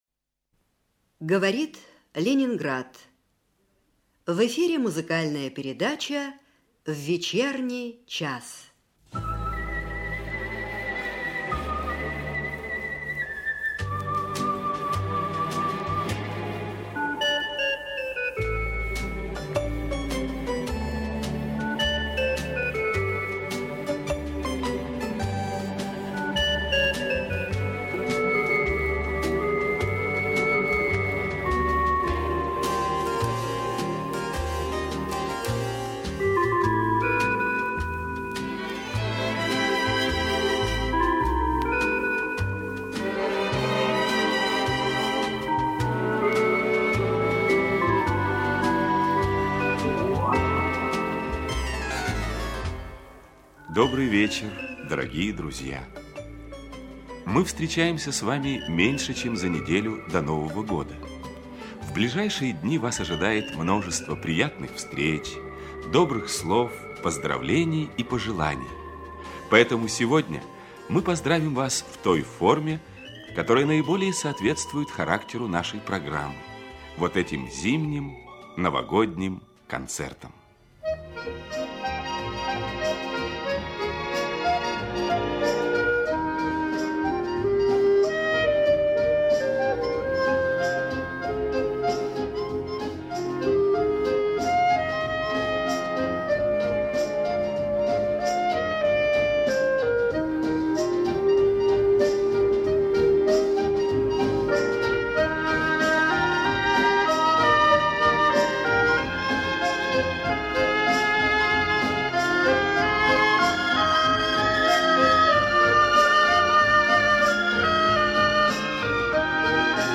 оркестр ЧССР